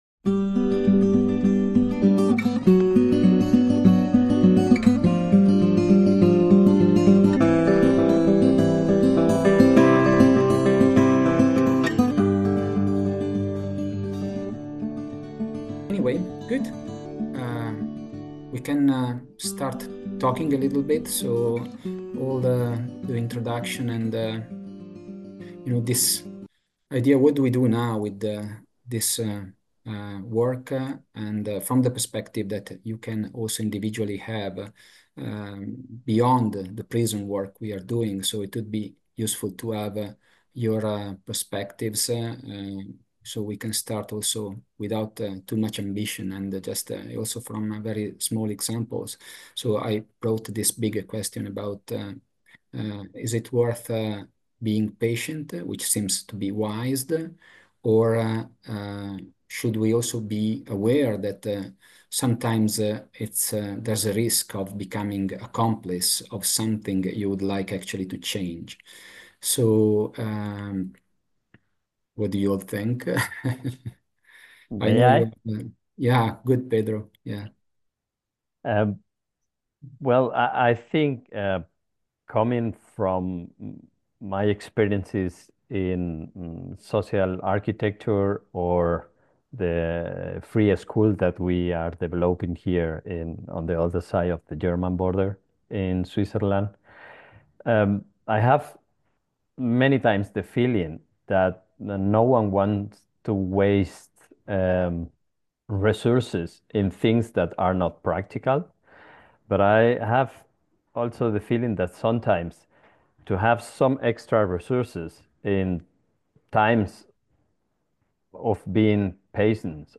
This episode is the recording of Runforever’s AGM on 6th June 2025.